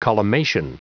Prononciation du mot collimation en anglais (fichier audio)
collimation.wav